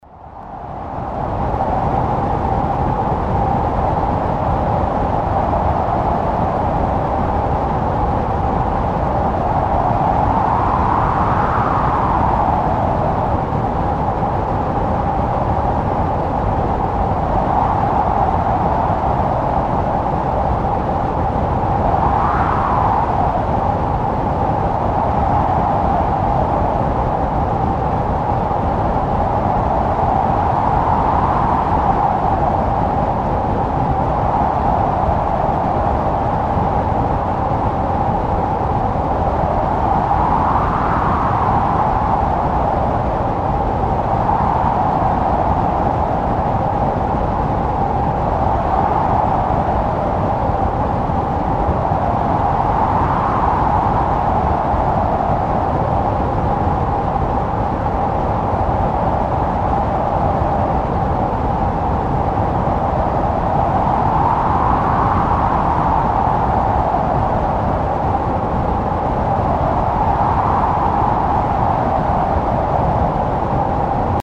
Звуки вьюги, метели
Метель и вьюга северного полюса